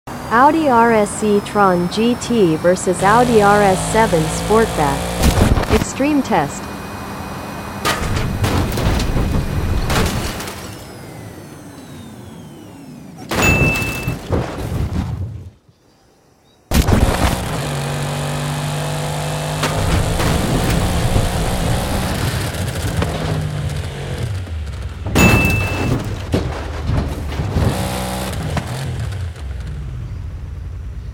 The 2021 Audi RS e tron sound effects free download
The 2021 Audi RS e-tron GT vs. The 2021 Audi RS 7 Sportback Part 4 in Forza Horizon 5 Using Xbox Wireless Controller Gameplay.